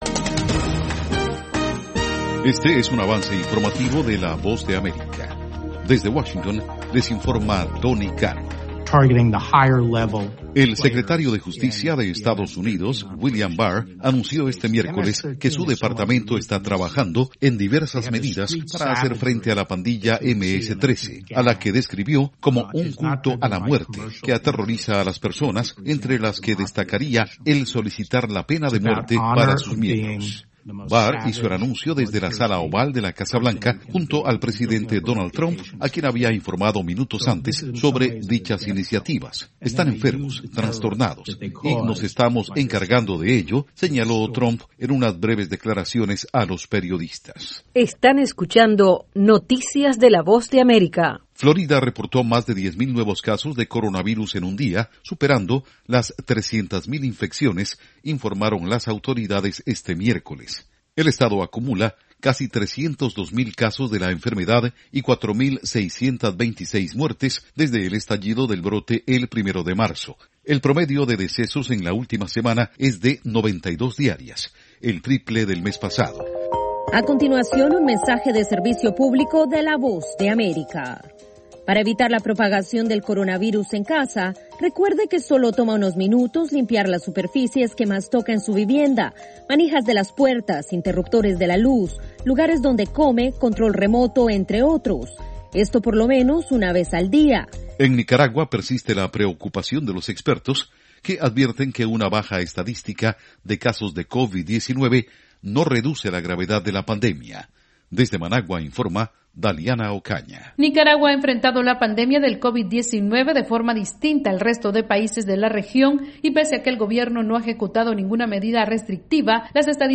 Algunas de las noticias de la Voz de América en este avance informativo: